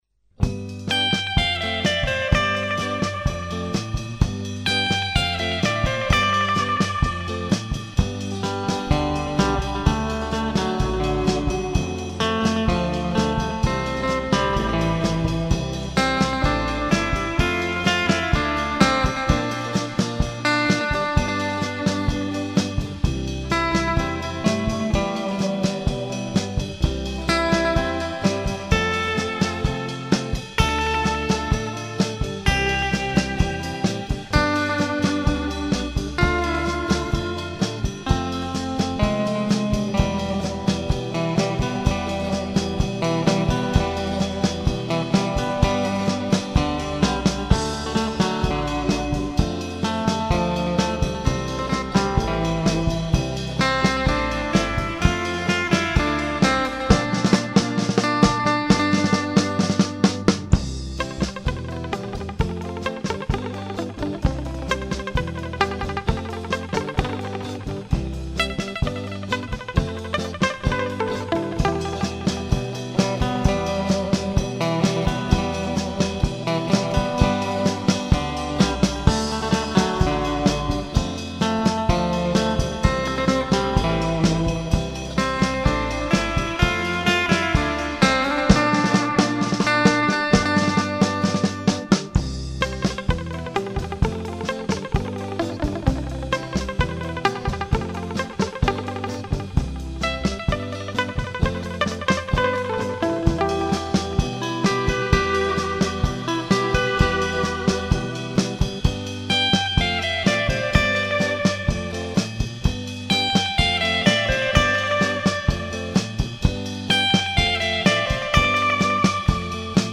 Guitare solo
Guitare rythmique
Guitare Basse / Vocals
Batterie